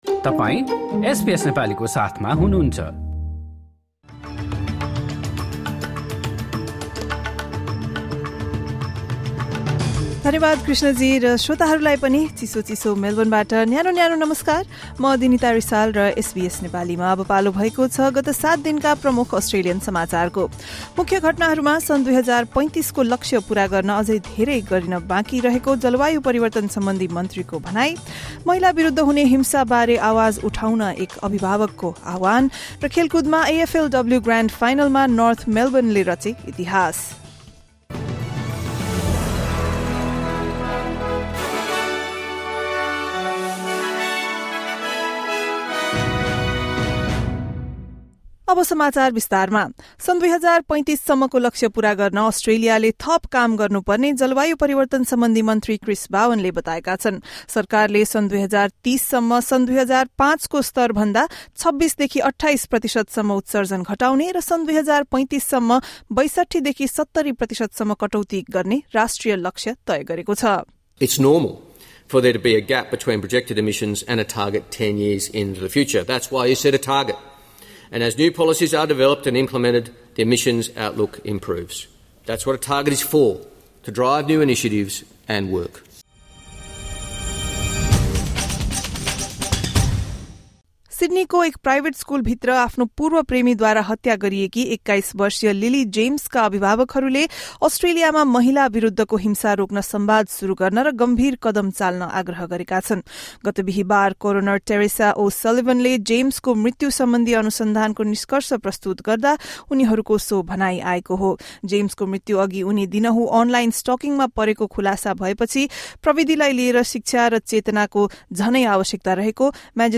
अस्ट्रेलियाले सन् २०३५ को लक्ष्य पूरा गर्न अझै धेरै काम गर्न बाँकी रहेको जलवायु परिवर्तन सम्बन्धी मन्त्रीको भनाइ, महिला विरुद्ध हुने हिंसा बारे आवाज उठाउन एक अभिभावक आह्वान र खेलकुदमा, एएफएलडब्लु ग्रान्ड फाइनलमा विजेता बन्दै नर्थ मेलबर्नले रचेको इतिहास लगायत एक हप्ता यताका प्रमुख घटनाहरू बारे एसबीएस नेपालीबाट समाचार सुन्नुहोस्।